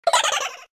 File:Super Mario Galaxy Boo laugh.oga
Sound effect from Super Mario Galaxy
Super_Mario_Galaxy_Boo_laugh.oga.mp3